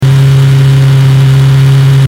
Car Engine
Car_engine.mp3